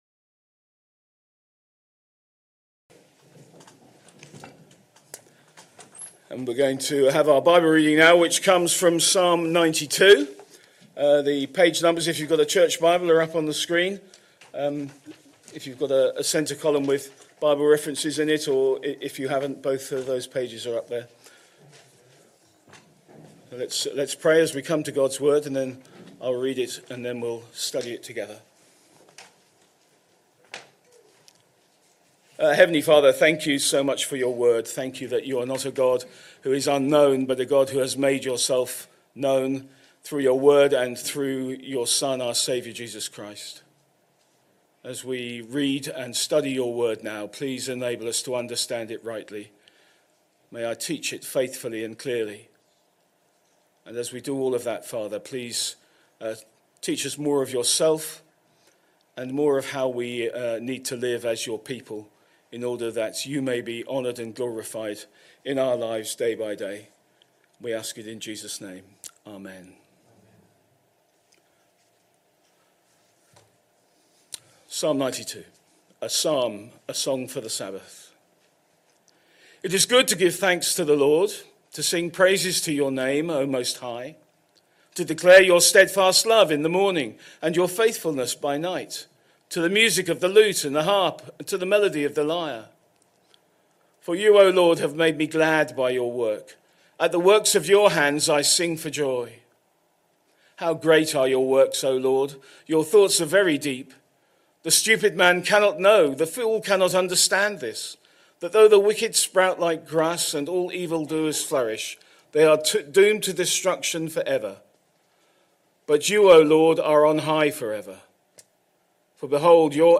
Sunday Evening Service Sunday 13th July 2025 Speaker